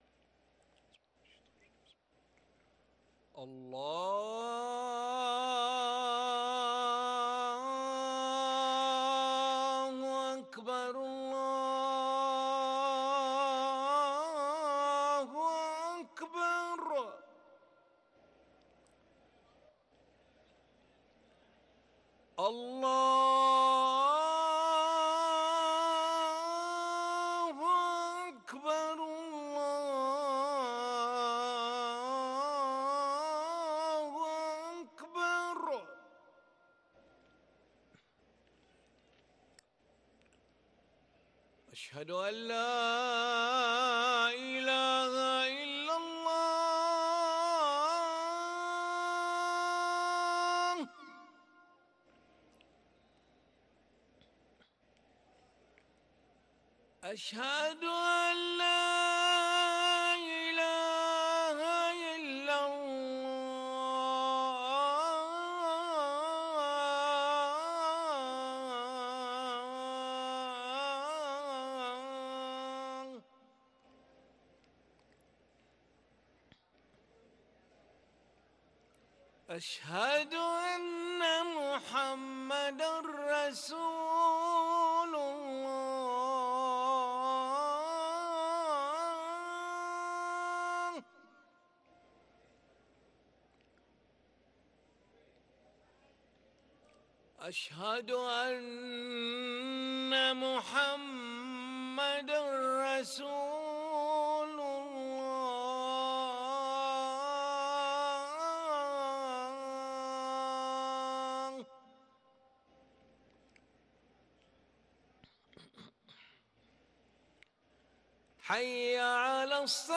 أذان العشاء للمؤذن علي ملا الخميس 12 جمادى الآخرة 1444هـ > ١٤٤٤ 🕋 > ركن الأذان 🕋 > المزيد - تلاوات الحرمين